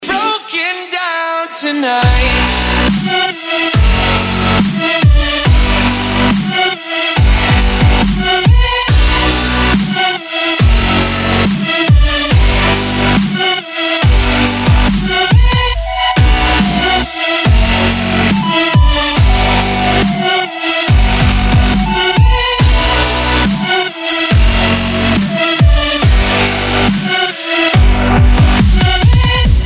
Dubstep рингтоны